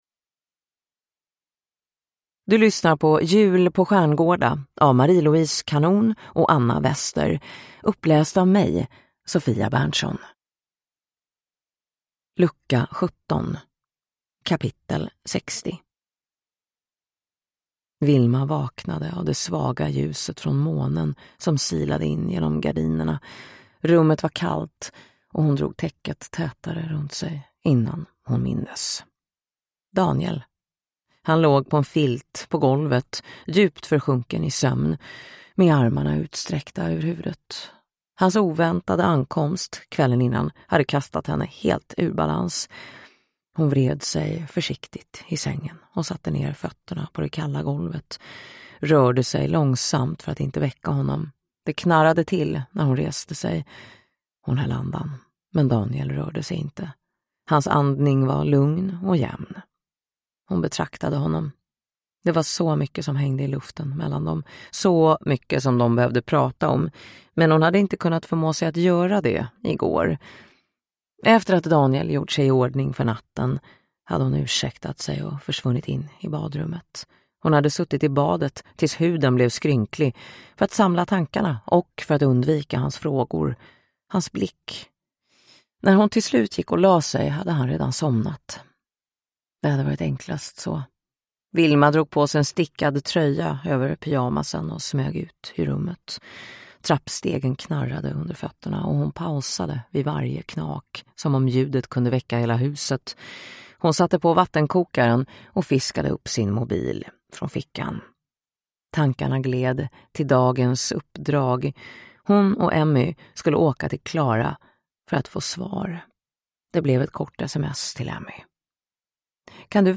Jul på Stjärngårda: Lucka 17 – Ljudbok